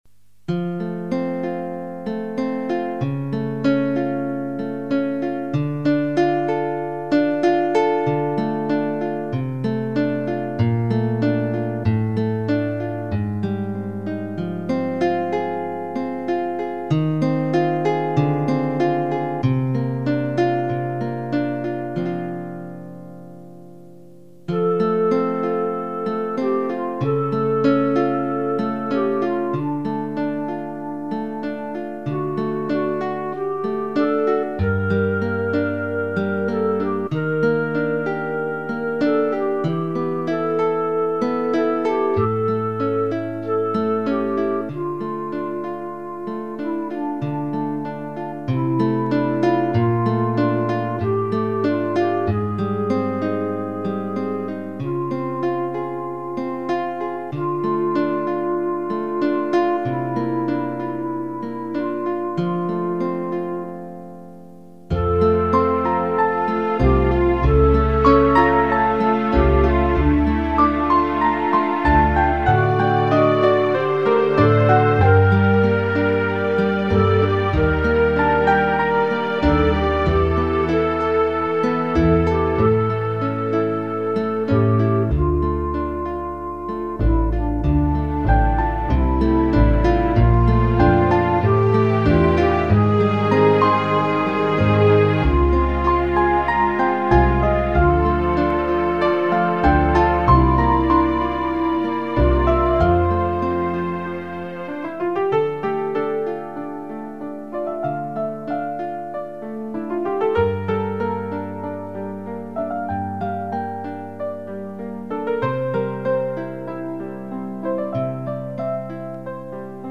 Lugn pianomusik.